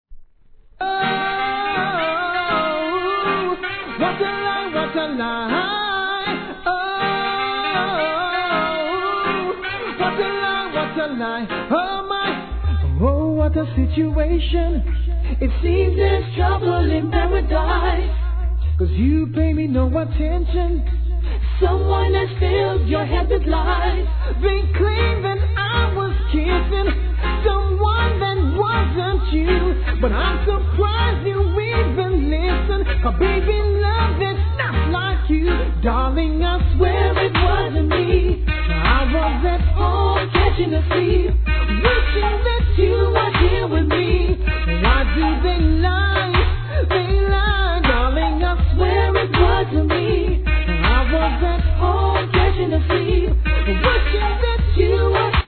REGGAE
GOOD SINGER物♪